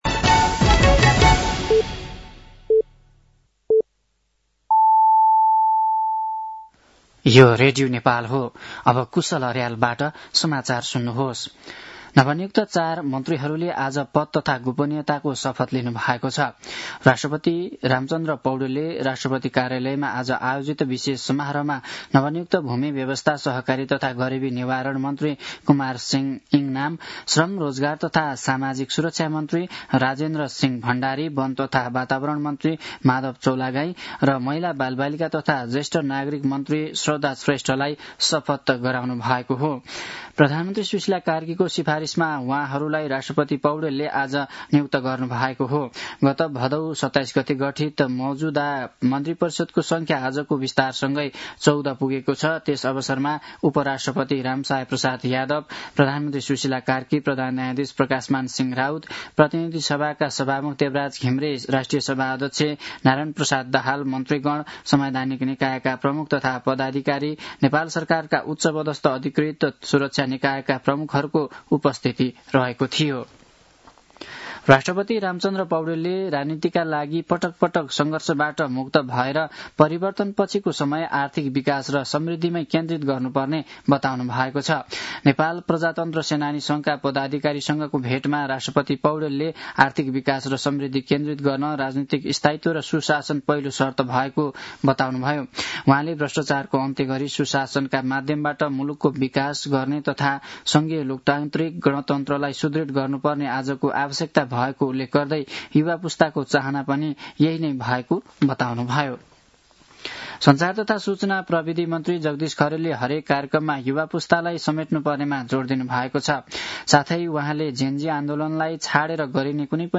साँझ ५ बजेको नेपाली समाचार : २६ मंसिर , २०८२
5-pm-nepali-news-8-26.mp3